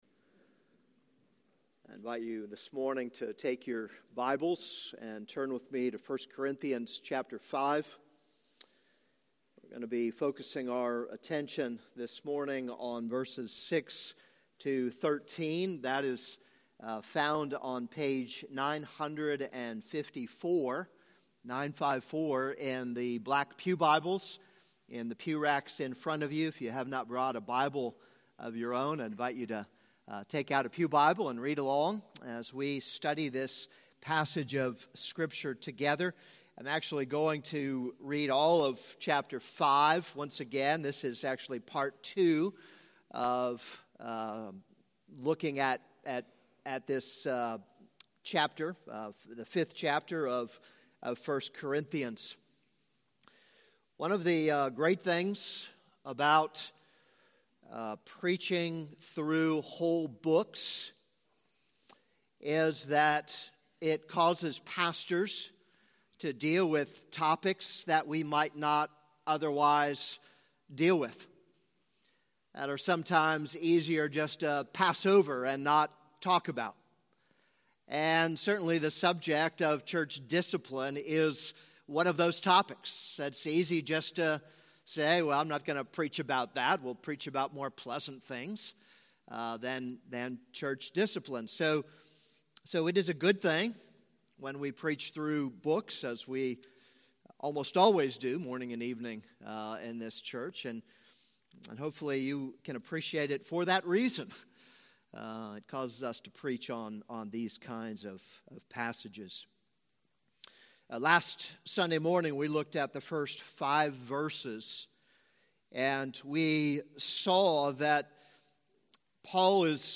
This is a sermon on 1 Corinthians 5:6-13.